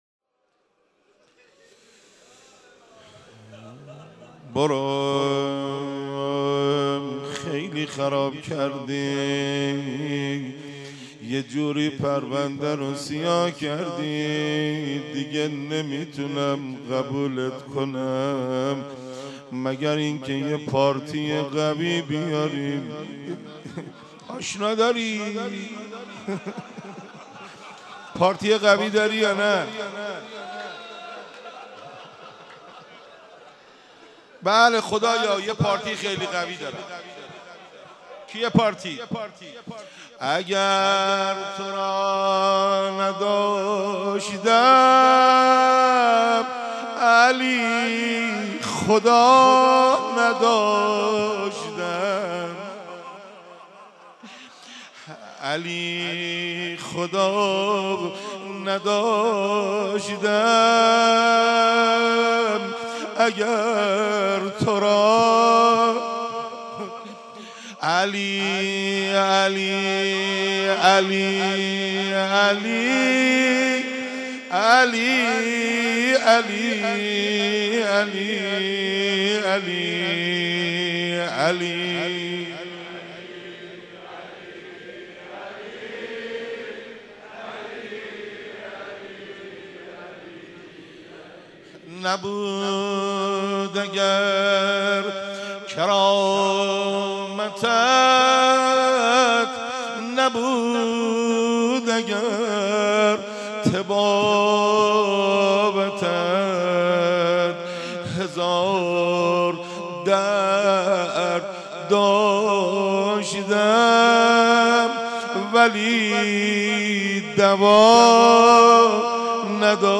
روضه
روضه شب 19 رمضان.mp3